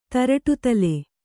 ♪ taraṭu tale